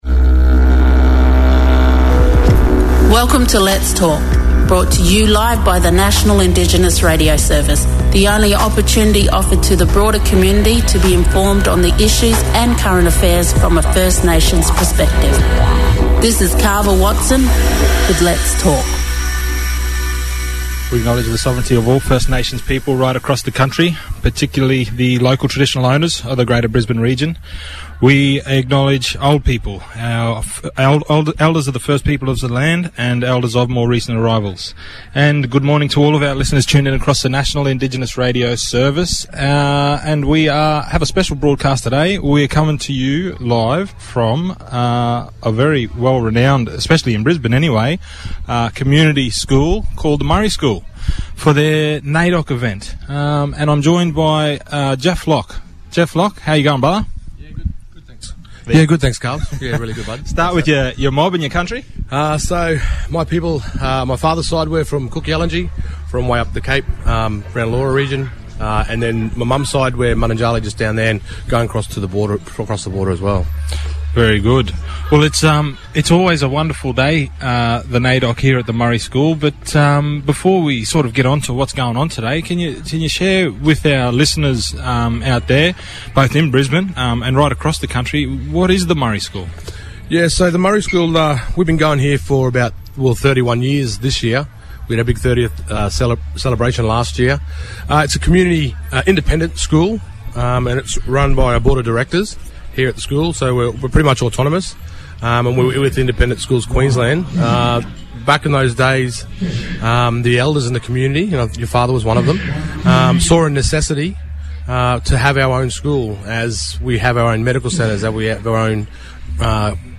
Live from the Murri School - Triple A